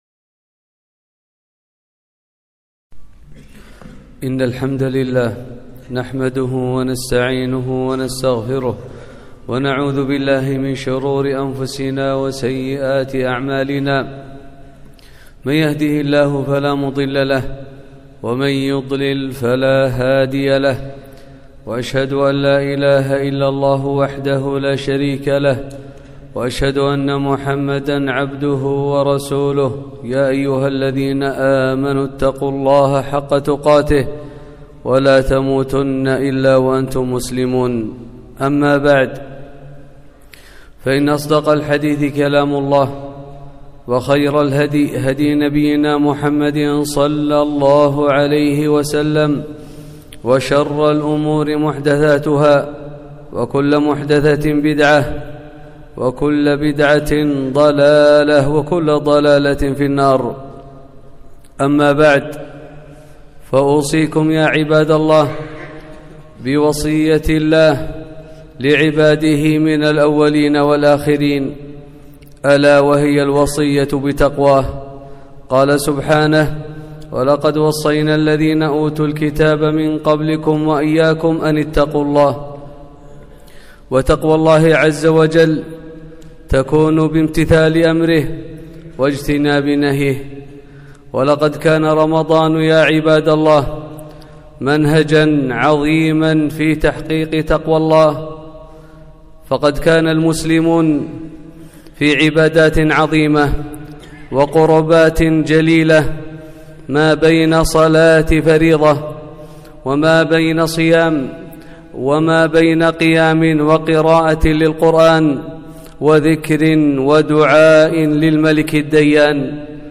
خطبة - خذوا من عبادتكم فى رمضان لسائر العام